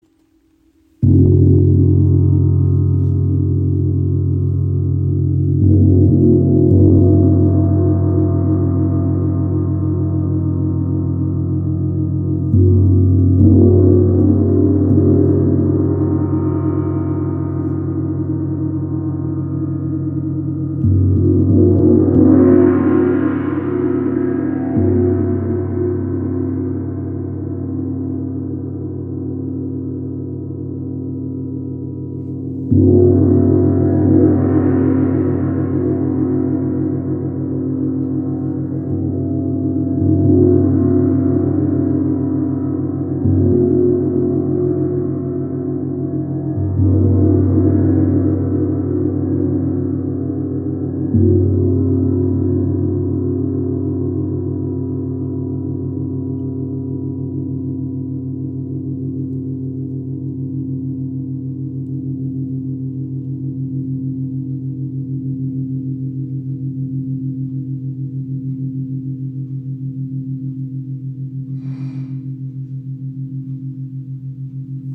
Tempel Gong | Tibetisches OM | 70 cm im Raven-Spirit WebShop • Raven Spirit
Klangbeispiel
Dieser ganz besondere Tempel Gong hat, wie der Chao oder Tam Tam Gong, einen gehämmerten Rand und wurde in Nepal im Kathmandutal erschaffen.